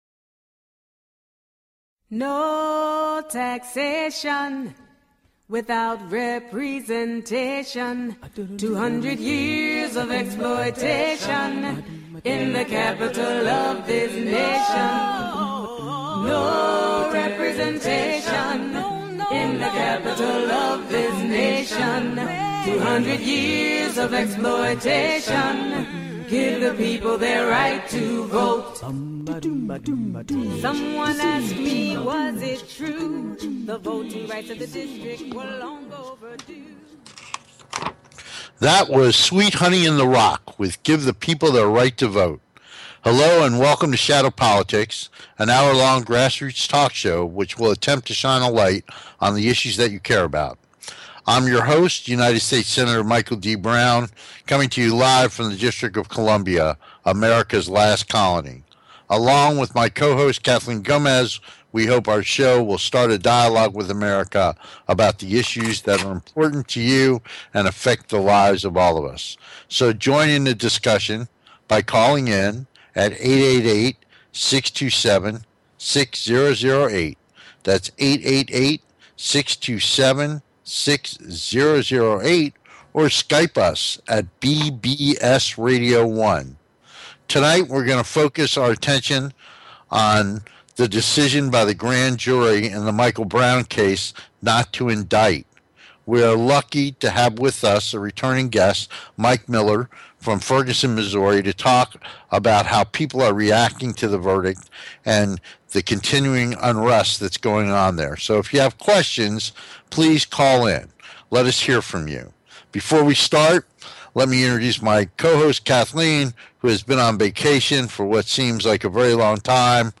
Shadow Politics is a grass roots talk show giving a voice to the voiceless.
At Shadow Politics, we hope to get this conversation started by bringing Americans together to talk about issues important to them. We look forward to having you be part of the discussion so call in and join the conversation.